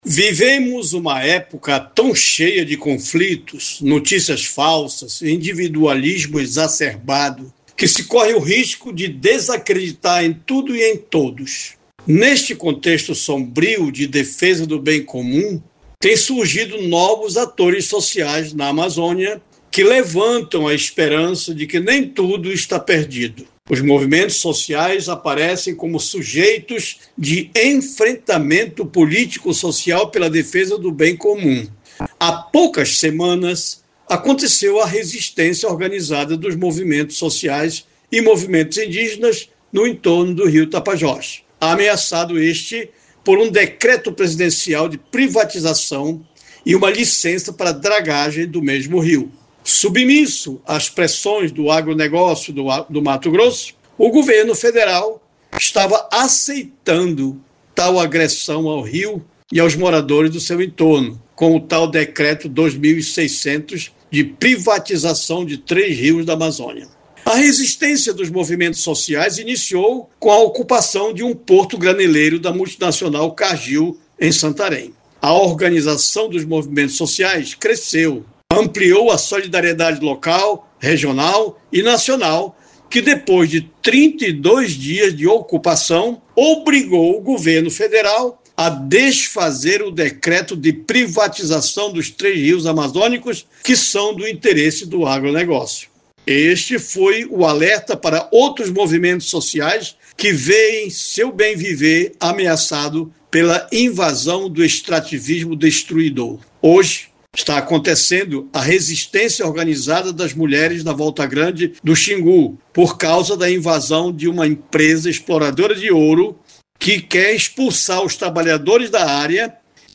EDITORIAL-12.mp3